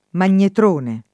vai all'elenco alfabetico delle voci ingrandisci il carattere 100% rimpicciolisci il carattere stampa invia tramite posta elettronica codividi su Facebook magnetron [ m # n’n’etron ] o magnetrone [ man’n’etr 1 ne ] s. m. (fis.)